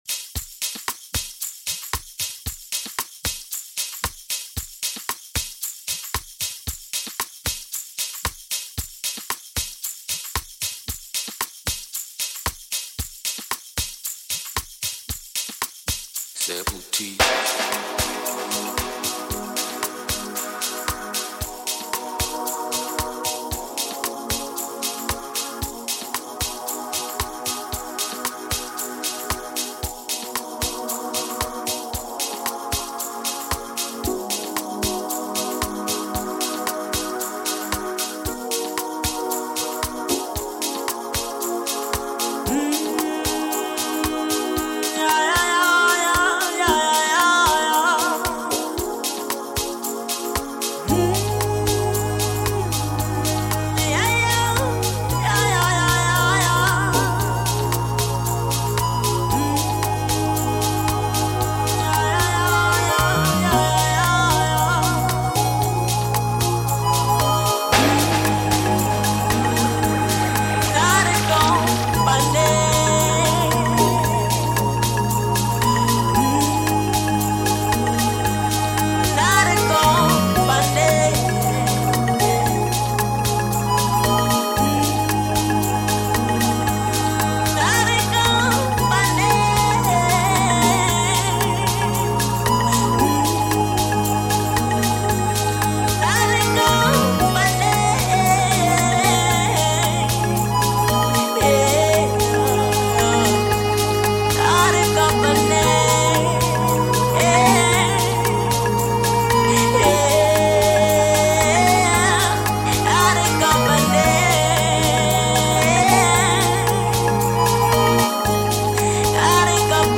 Limpopo’s Amapiano and Afro pop sensation